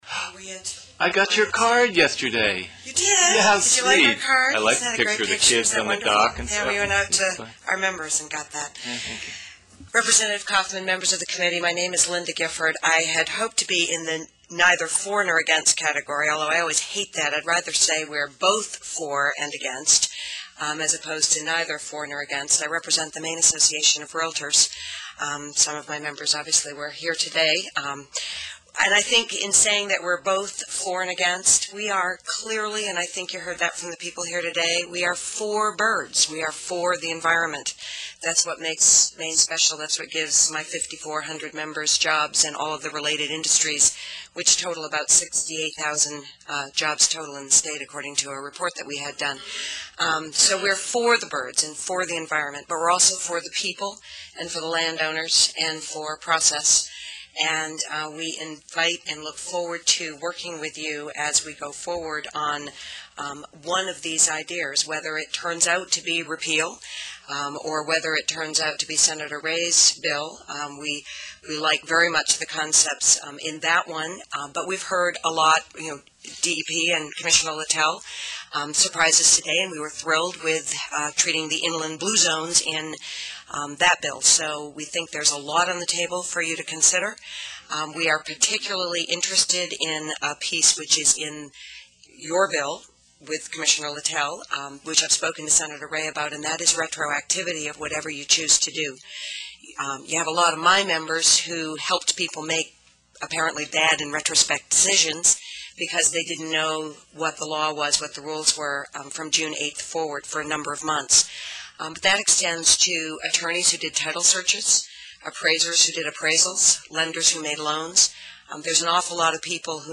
Maine legislature hearing on revisions of shorebird habitat land takings